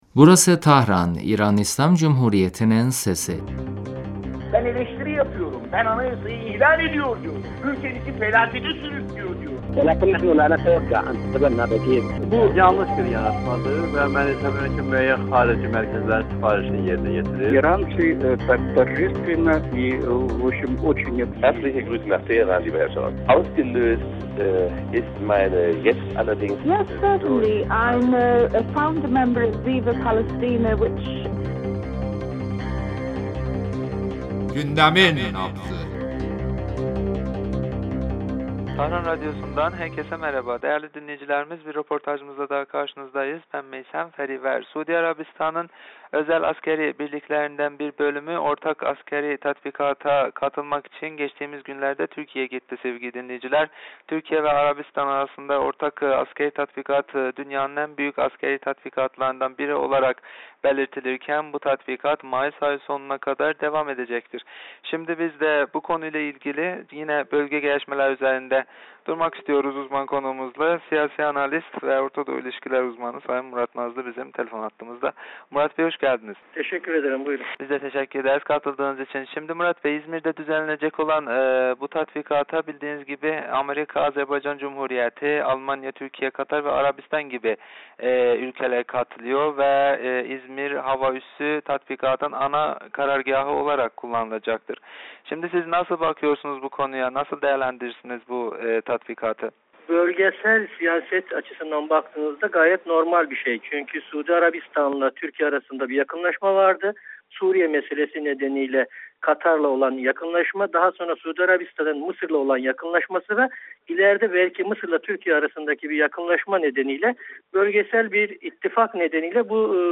radyomuza verdiği demecinde son günlerde Türkiye - Arabistan arasında düzenlenen ortak askeri tatbikatı konusunuda görüşlerini bizimle paylaştı.